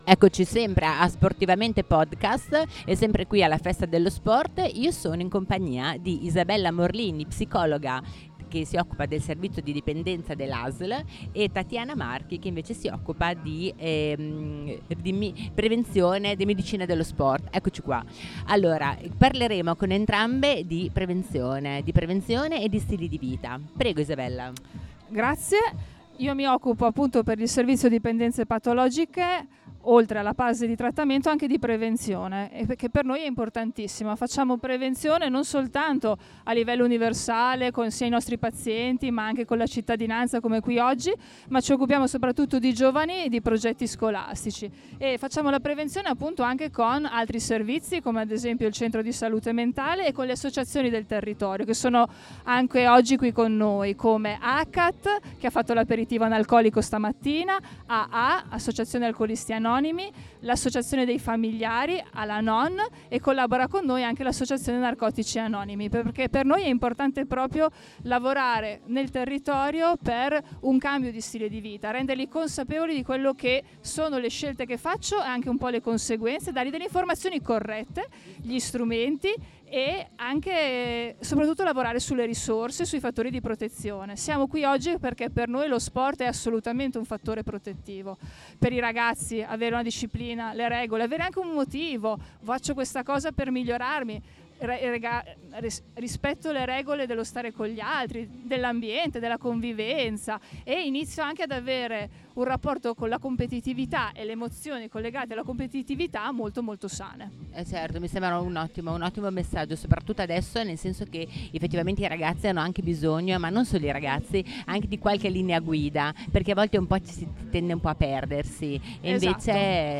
Festa dello sport 2025
Intervista